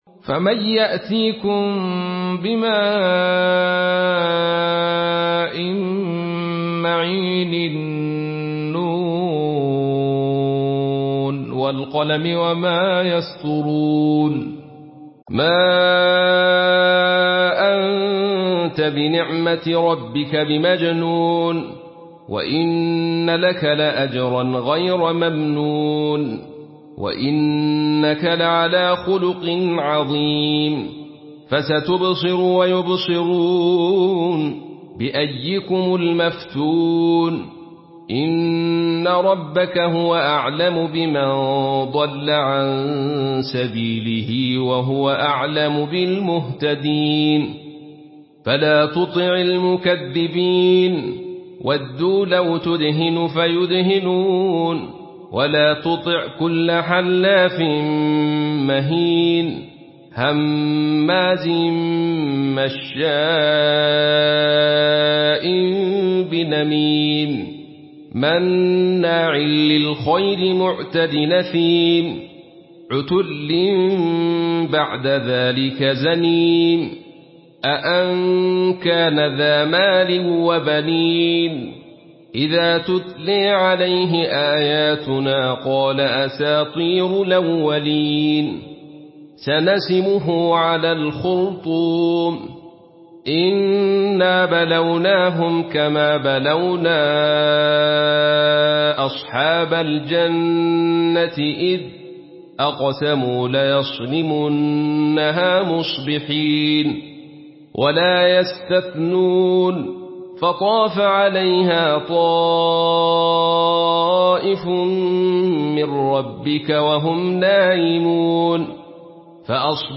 Surah Al-Qalam MP3 in the Voice of Abdul Rashid Sufi in Khalaf Narration
Murattal